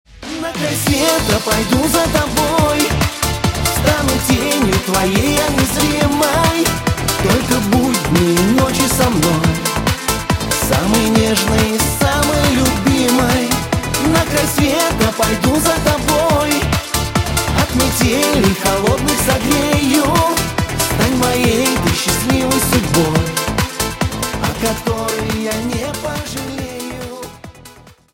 шансон рингтоны
романтичные